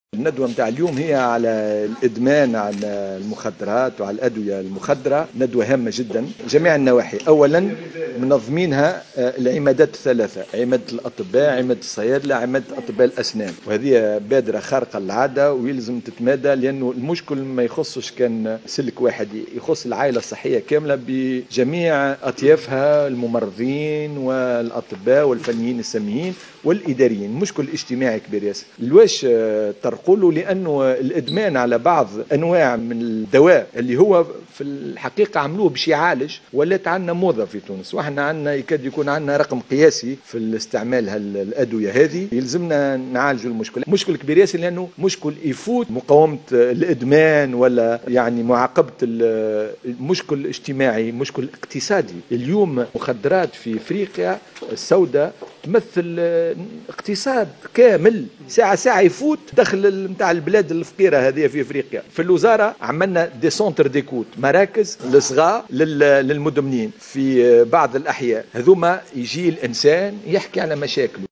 Mohamed Salah Ben Ammar, ministre de la santé publique a indiqué ce jeudi 16 octobre 2014, lors d’une rencontre organisée à Sousse, que la Tunisie a enregistré une importante augmentation au niveau de la consommation des médicaments classés comme drogues.